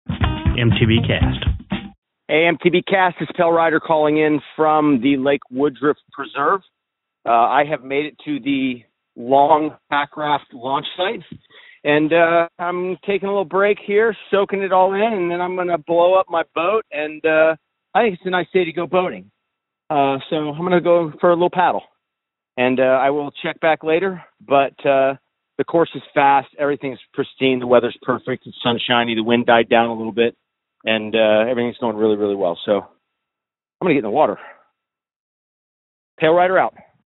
called in from Lake Woodruff Preserve!